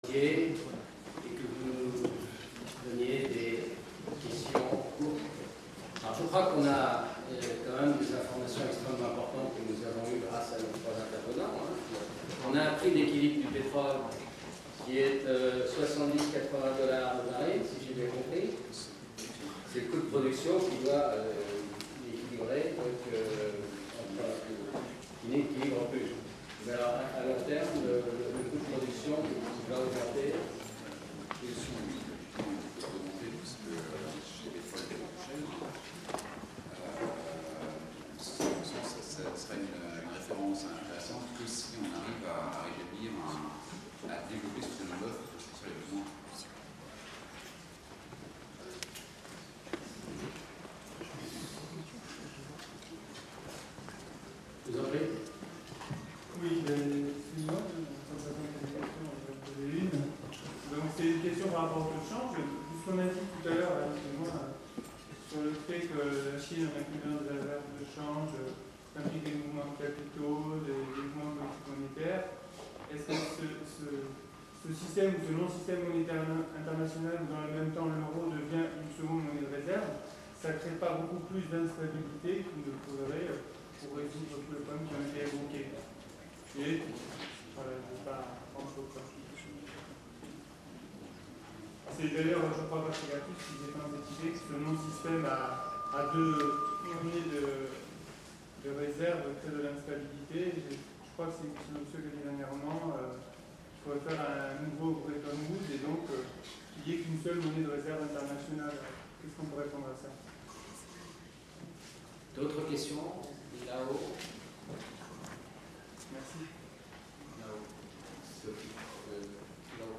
Questions - Débat